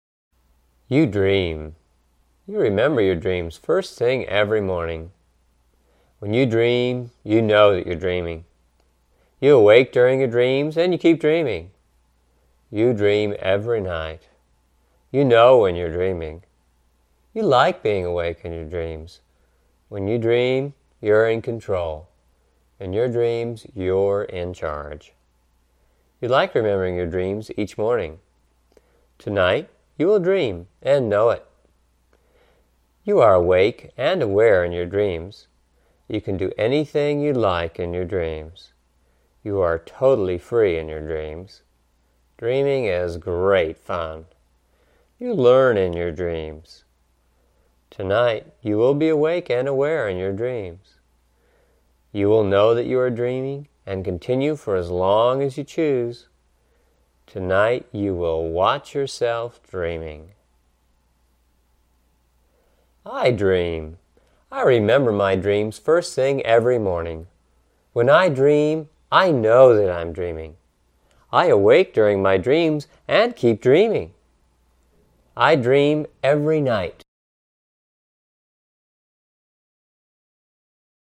An audible version of the suggestions that are used in the subliminal. Suggestions such as "I am awake and aware in my dreams." "I am totally free in my dreams." "Dreaming is great fun." "In my dreams, I'm in charge." "I learn in my dreams." etc. 32 minutes $11.95 Our 100% money back guarantee applies.